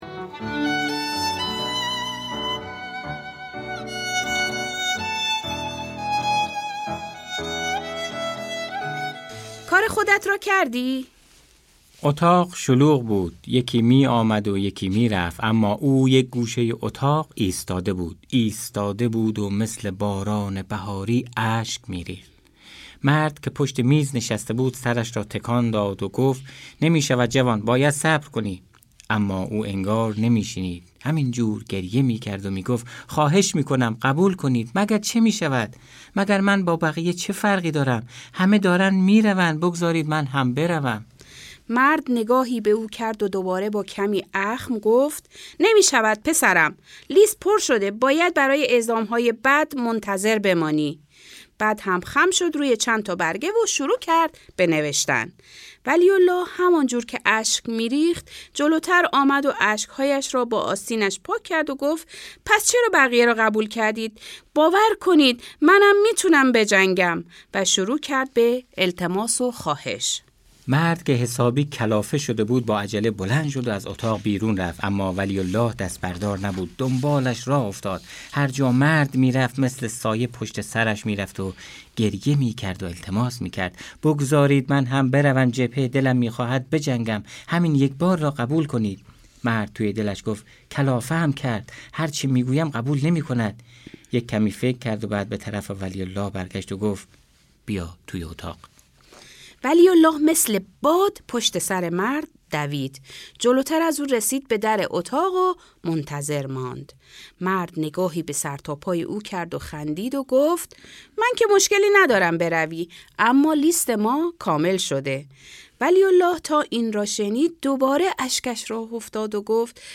قصه های قهرمان ها«مثل رستم دستان»